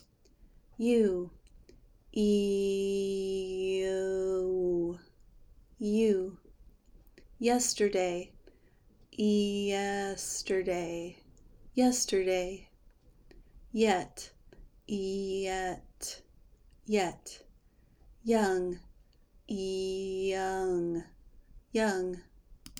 Pronounce Y in American English
In words, this sound is spoken quickly. You will keep the long e sound, and the “uh” will be present, though very reduced.
Practice these words with Y